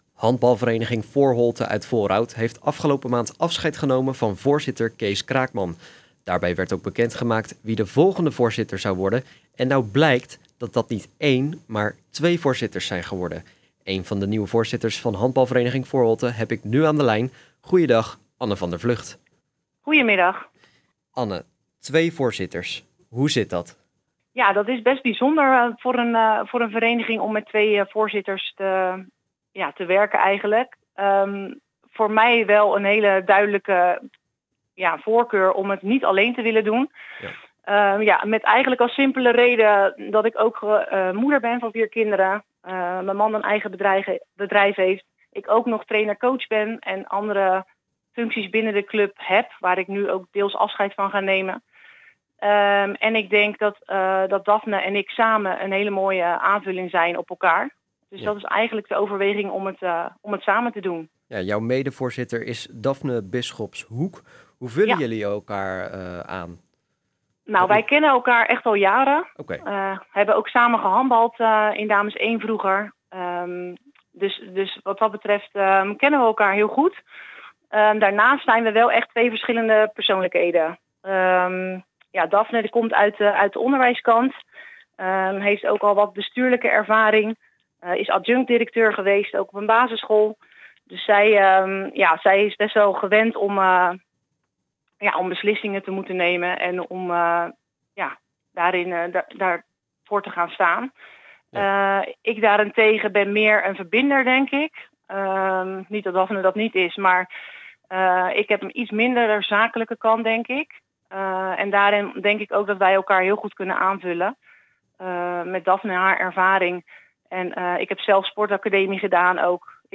[AUDIO] Handbalvereniging Foreholte krijgt twee voorzitters: "op en top clubvrouwen" - Bollenstreek Omroep